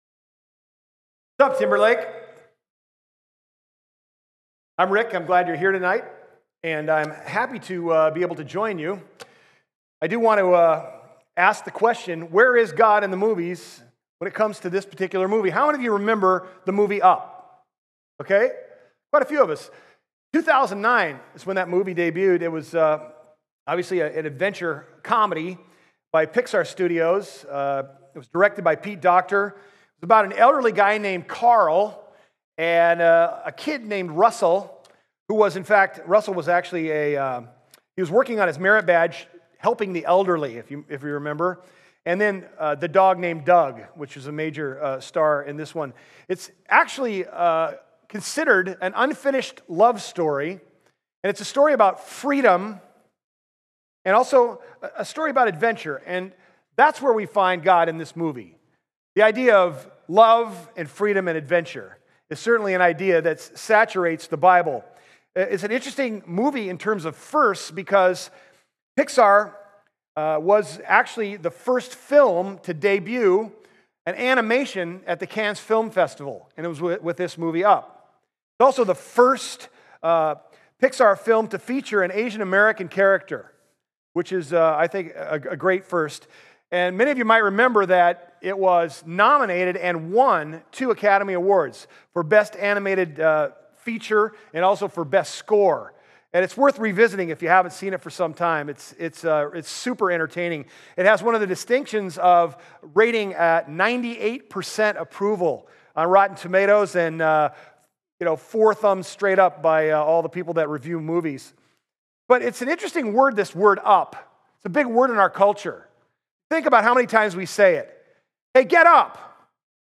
(Guest Pastor)